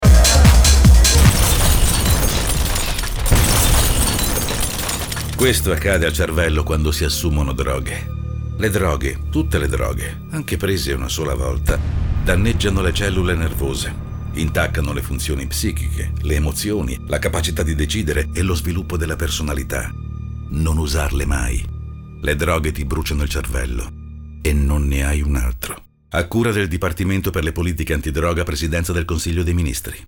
Presentazione
La musica inizia a saltare, come se ci fossero cali di corrente. Rumore e sfrigolio di elettricità. Poi silenzio.